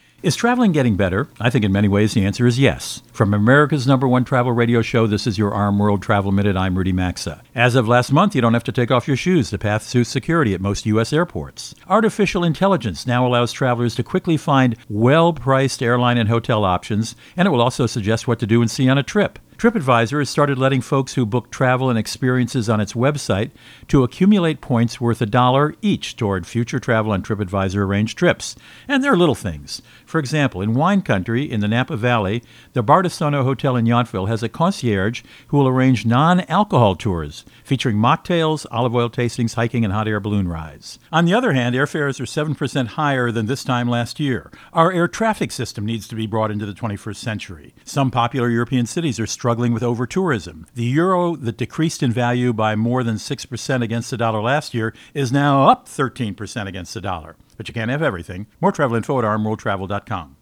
Co-Host Rudy Maxa | Better Travel?